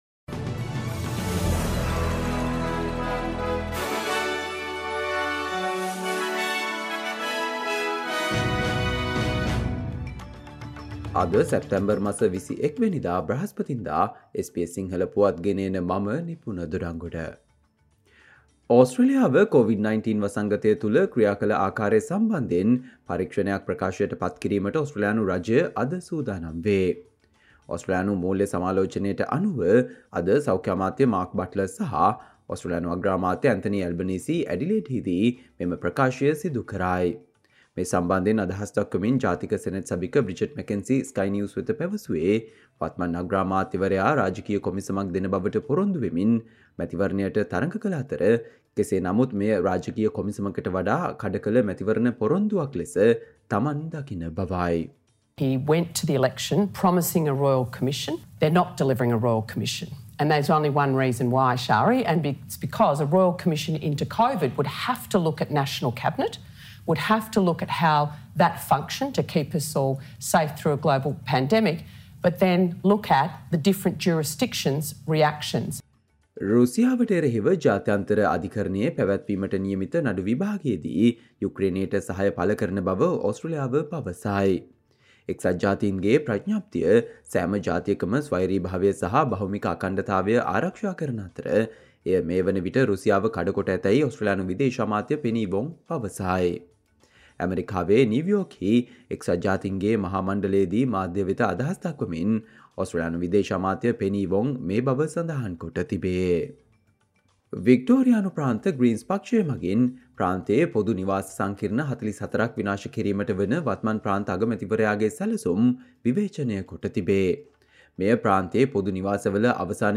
Australia news in Sinhala, foreign and sports news in brief - listen, today - Thursday 21 September 2023 SBS Radio News